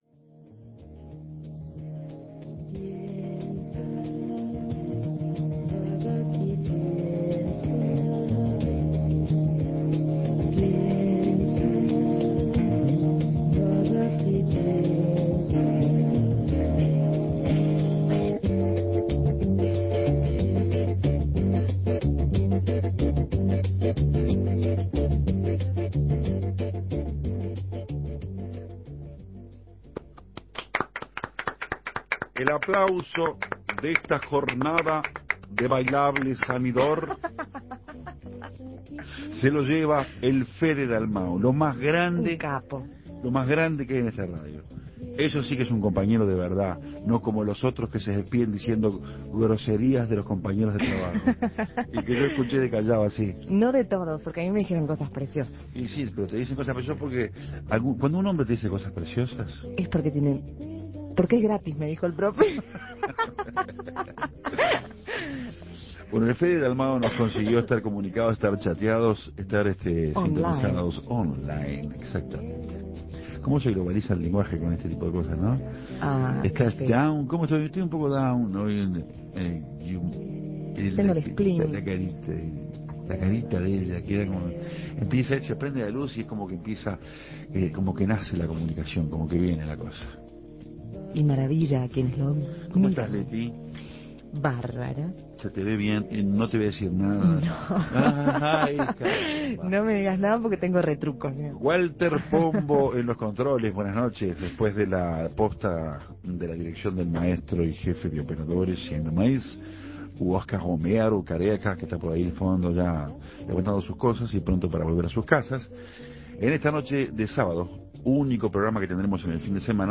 Varias mujeres uruguayas suenan bien en Café Torrado. Voces, guitarras, músicas, colores...